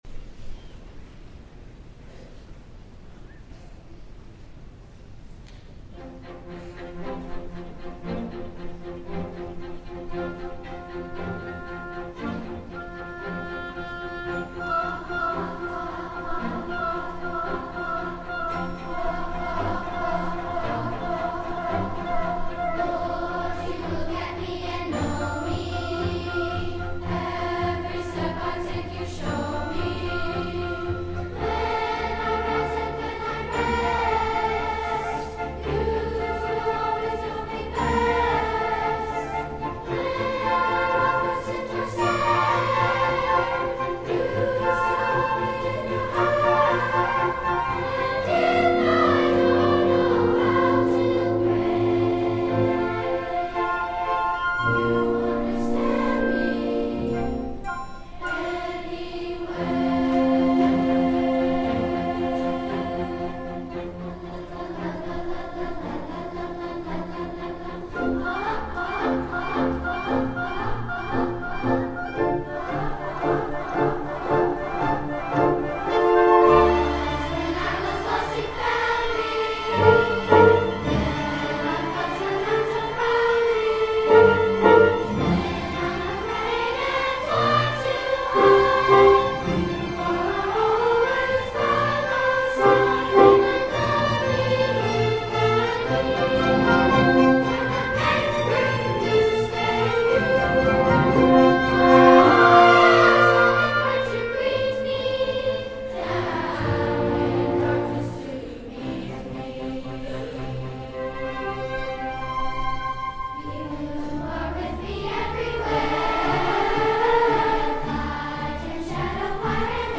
for Two-Part Treble Chorus and Orchestra (2010)